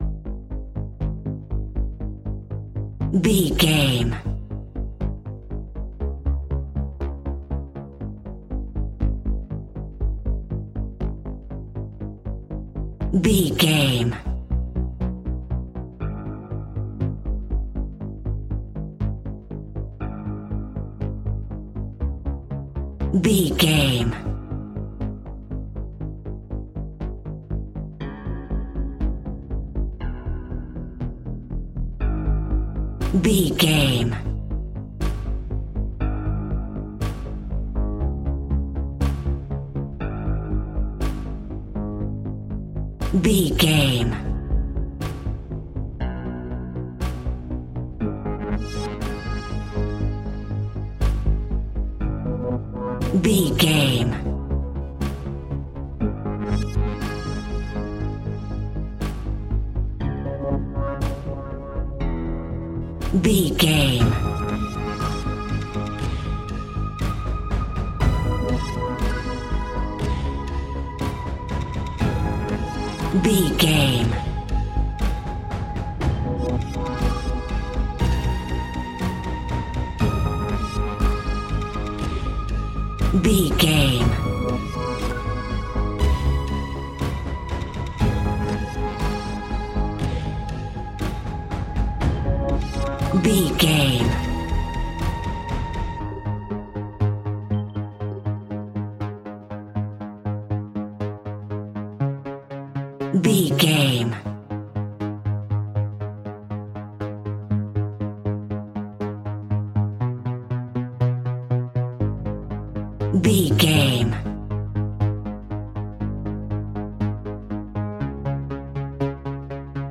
In-crescendo
Thriller
Aeolian/Minor
ominous
dark
eerie
horror music
Horror Pads
horror piano
Horror Synths